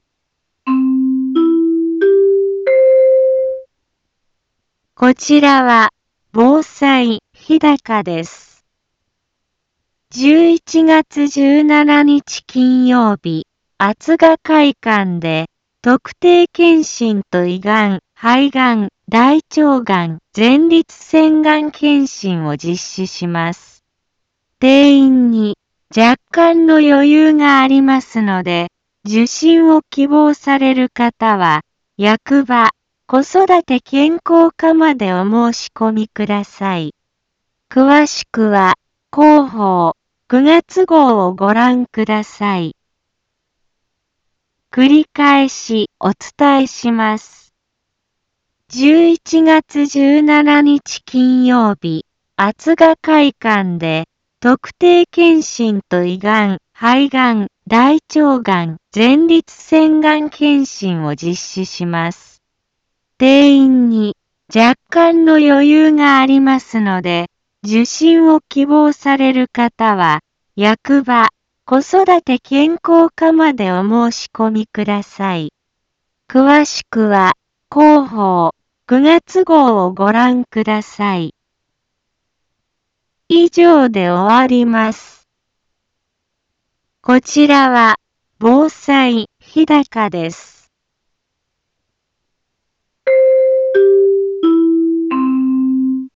Back Home 一般放送情報 音声放送 再生 一般放送情報 登録日時：2023-10-05 10:03:23 タイトル：特定検診・がん検診のお知らせ インフォメーション： 11月17日金曜日厚賀会館で、特定検診と胃がん・肺がん・大腸がん・前立腺がん検診を実施します。